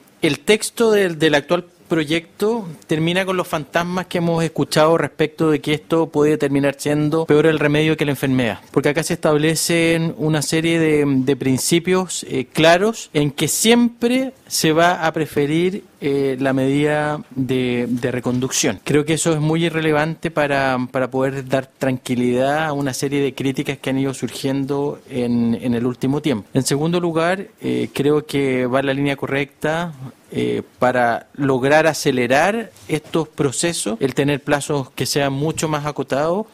La Comisión de Gobierno Interior del Senado continuó este lunes la discusión del proyecto que modifica la Ley de Migraciones para tipificar el ingreso clandestino al territorio nacional como delito.
Por su parte, el senador Renzo Trisotti valoró que el proyecto privilegie la reconducción inmediata de extranjeros antes que otros procedimientos y planteó dudas respecto de la ejecución de las órdenes de expulsión pendientes.